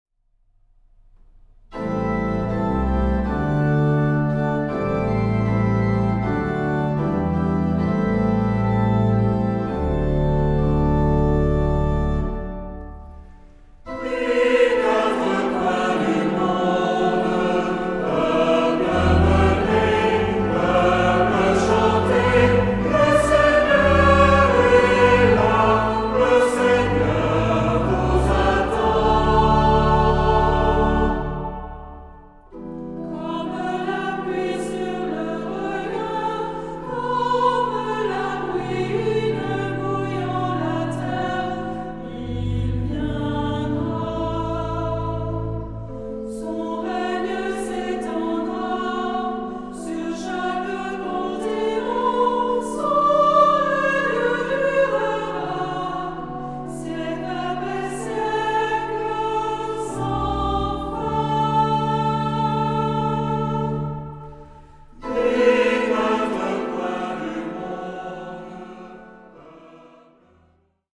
(1 voix unisson) ; Partition complète.
Cantique. Sacré.
Orgue (1)
Tonalité : modal